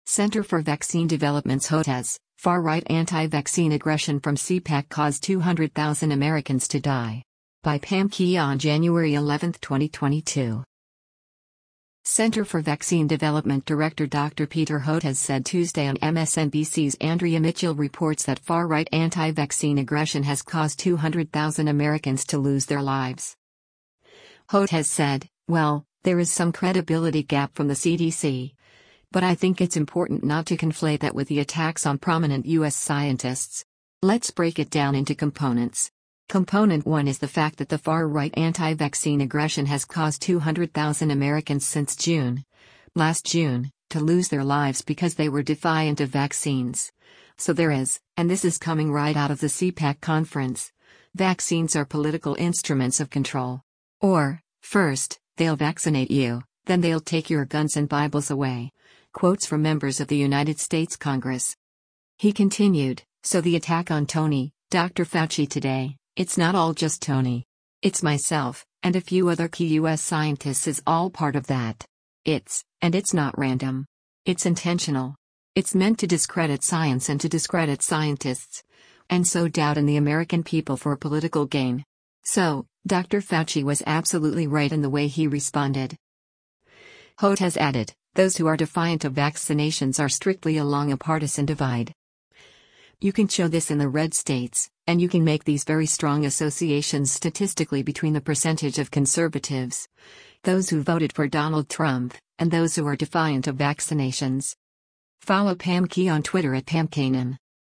Center for Vaccine Development director Dr. Peter Hotez said Tuesday on MSNBC’s “Andrea Mitchell Reports” that “far-right anti-vaccine aggression has caused 200,000 Americans” to lose their lives.